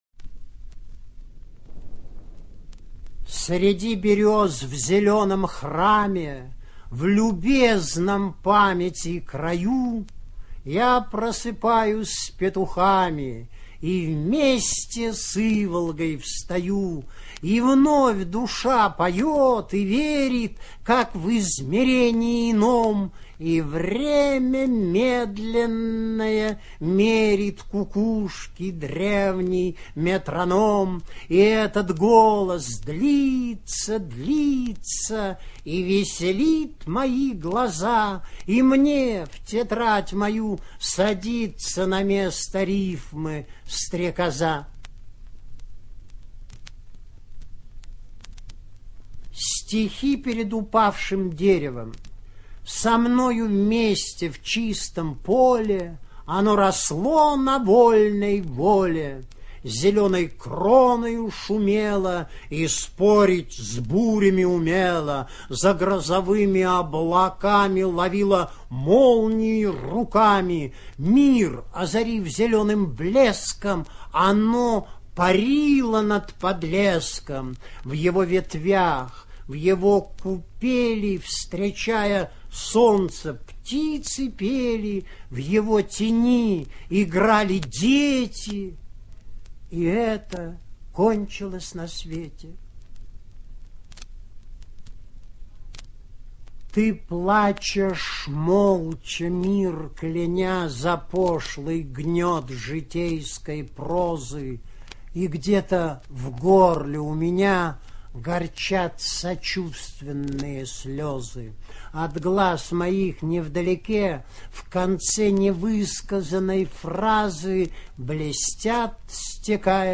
ЧитаетАвтор
ЖанрПоэзия